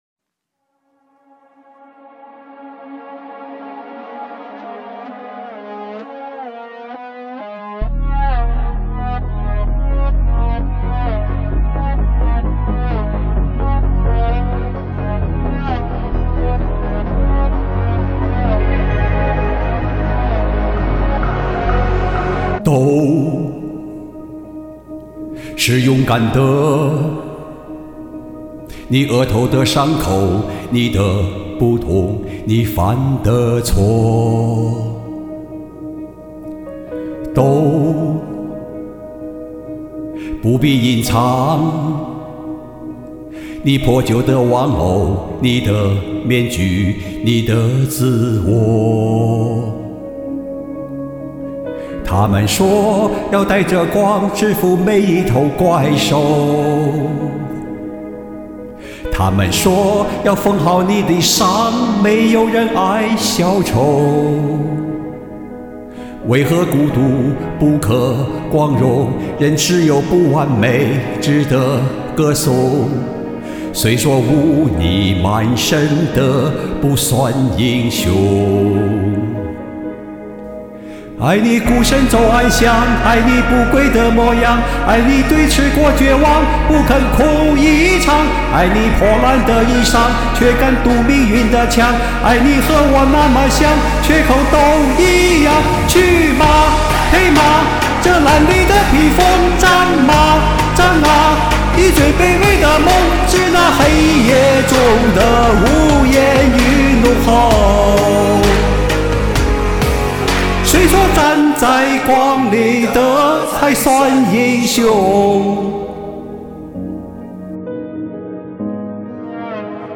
满满的阳刚之气！